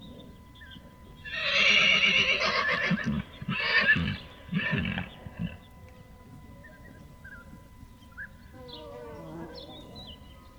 Nightingale song
Category 🌿 Nature
bird birdsong field-recording forest nature nightingale spring sound effect free sound royalty free Nature